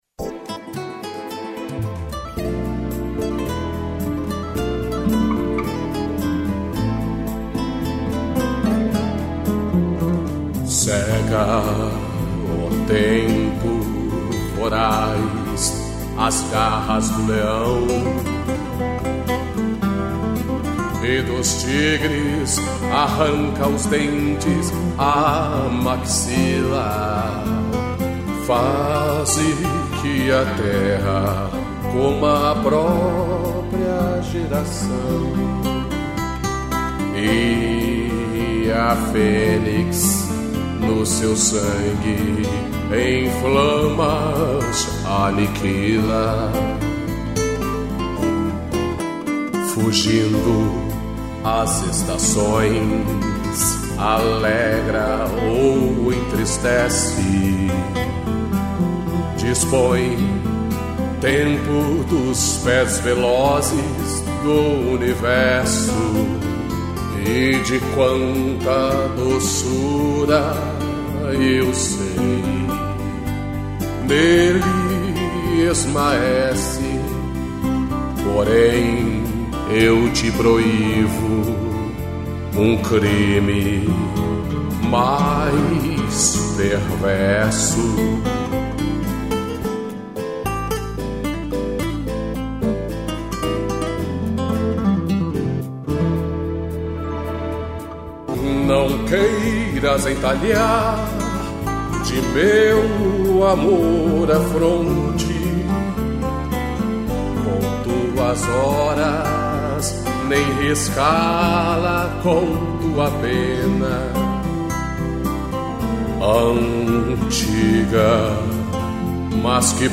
violão e voz